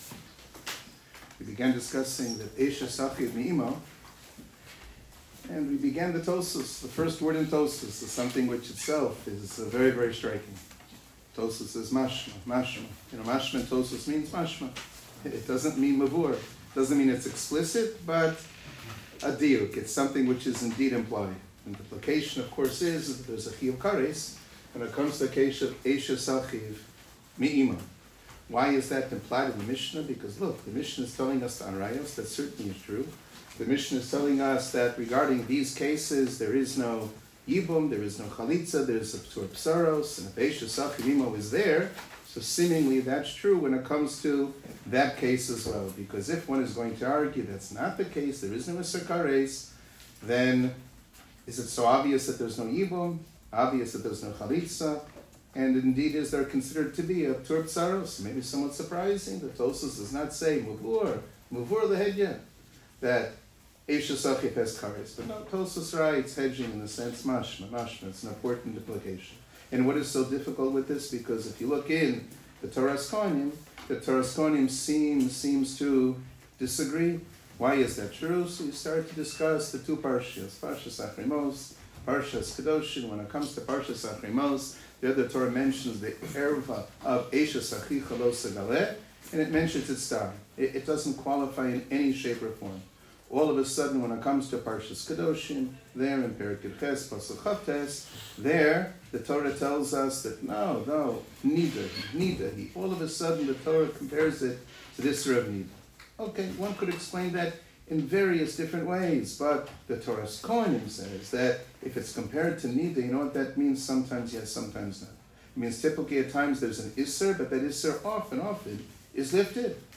Shiur 3 - אשת אחיו מאמו חלק ב